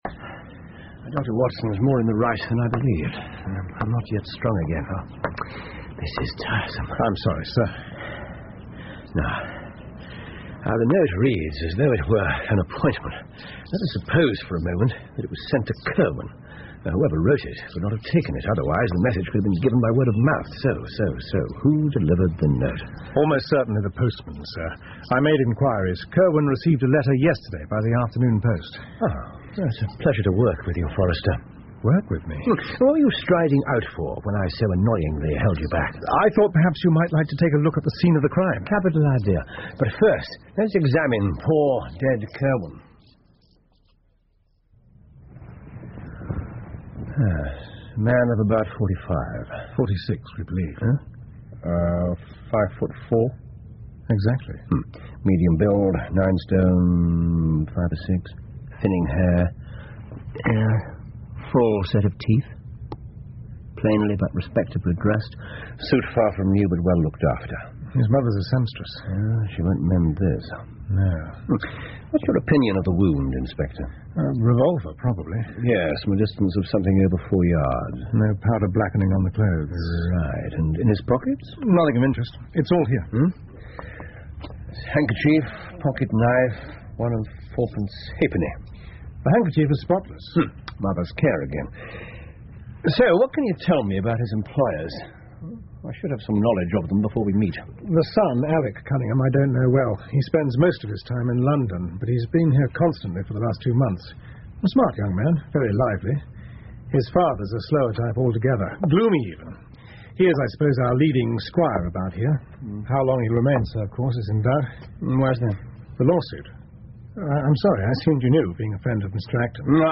福尔摩斯广播剧 The Reigate Squires 4 听力文件下载—在线英语听力室